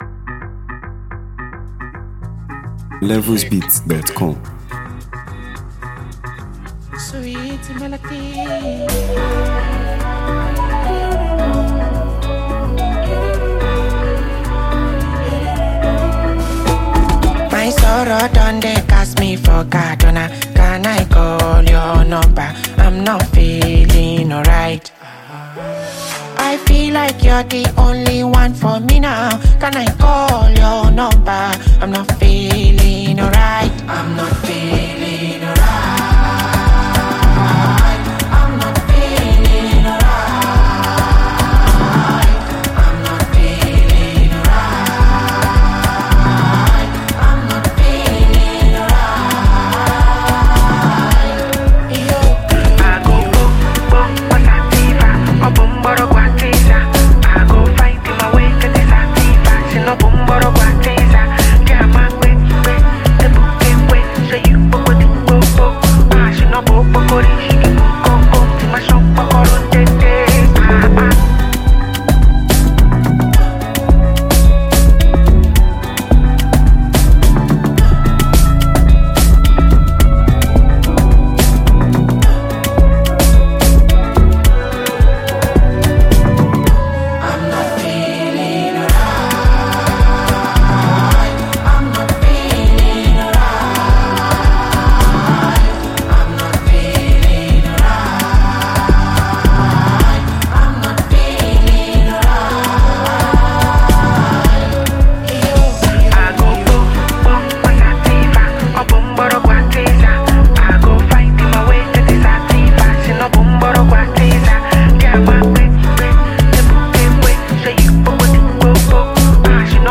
Sensational Nigerian singer-songwriter
Known for his smooth vocals and refreshing musical style
if you enjoy well-crafted and melodious music